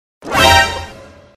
pause-back-click - Copy.mp3